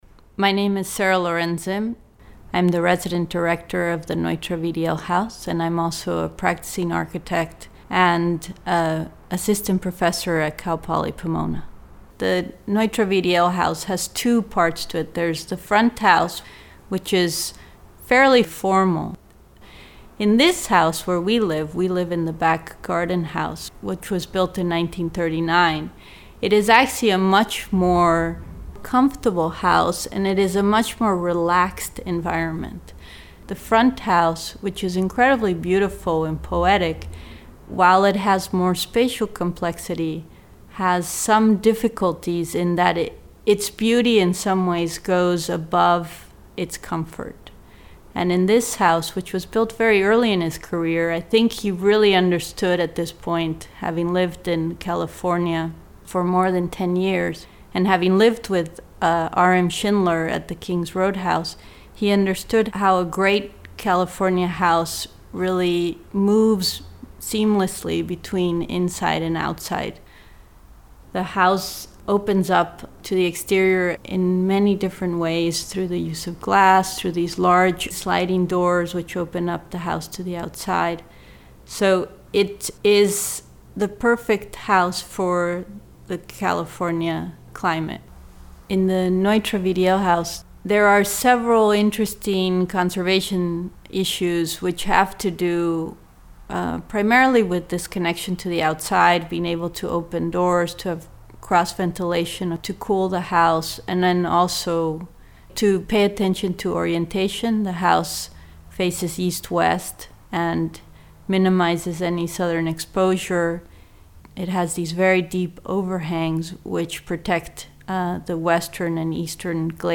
The following six interviews, each approximately four minutes long, provide a series of different point of views for looking at the exhibition, Richard Neutra, Architect: Sketches and Drawings.